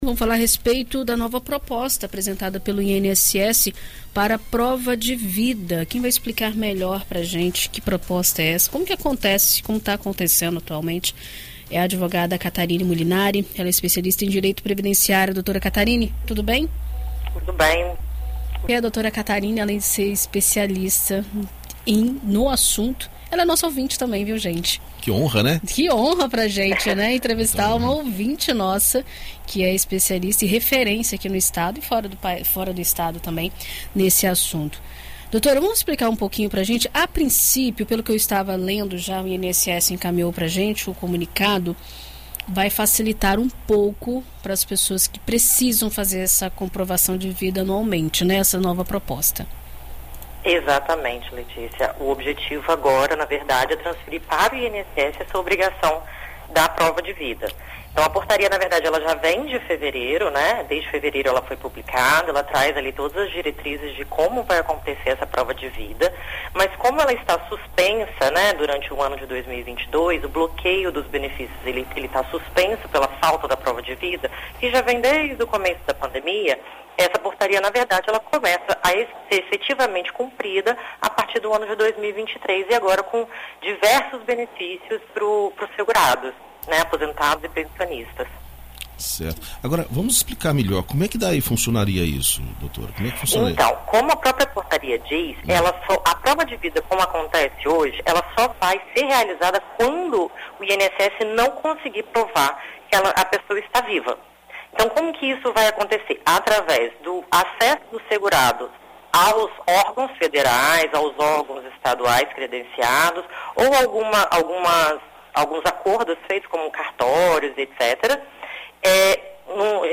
Em entrevista à BandNews FM Espírito Santo nesta terça-feira